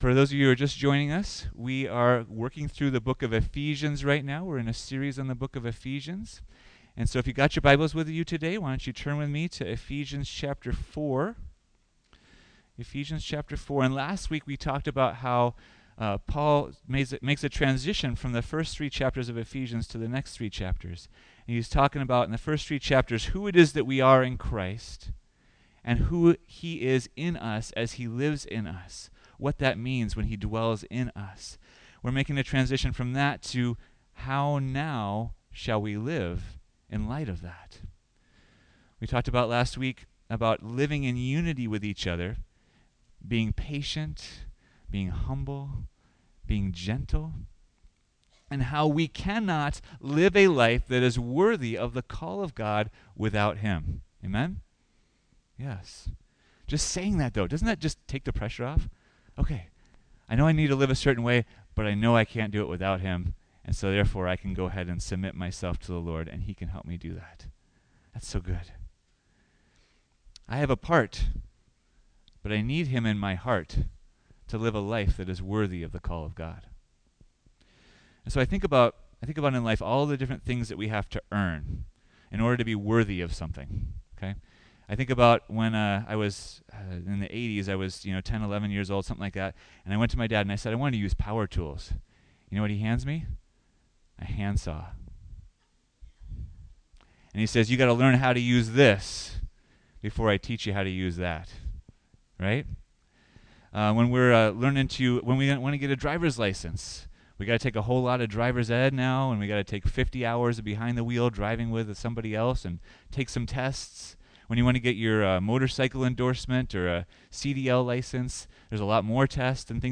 2023 Becoming Mature Together Preacher